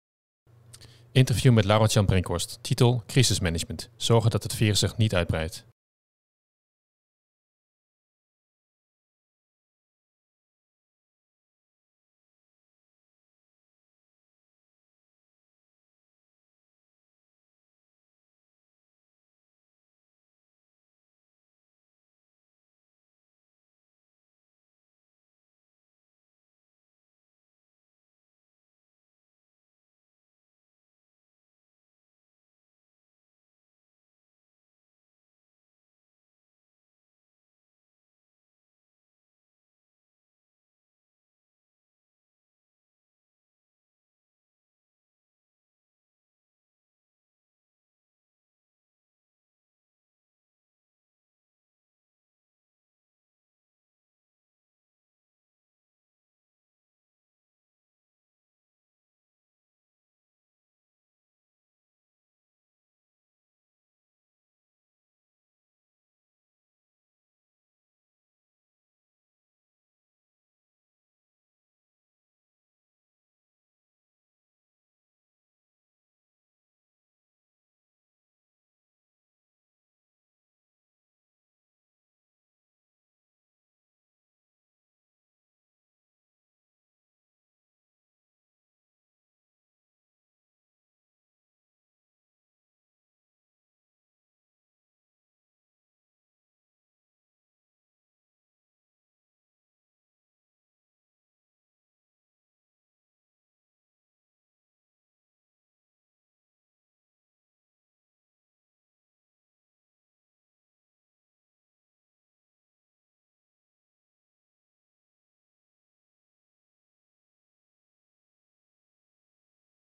Interview met Laurens Jan Brinkhorst.